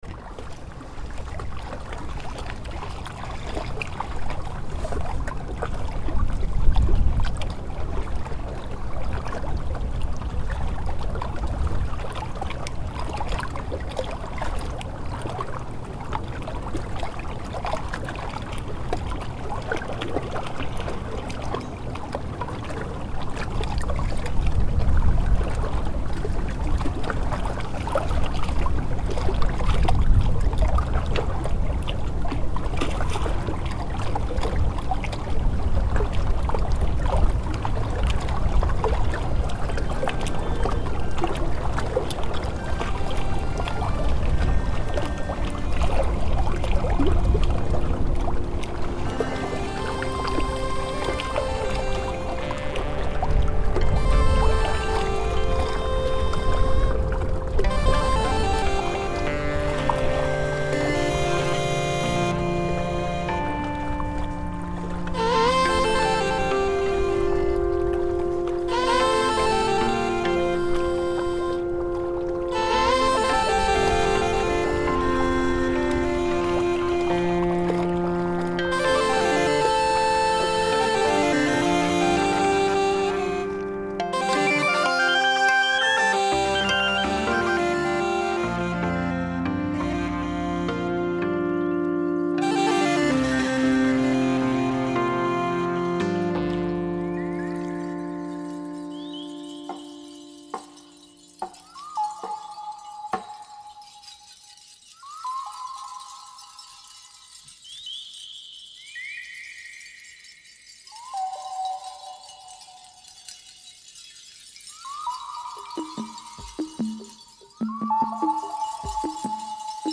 Transmissions from the Olympic Peninsula. Field recordings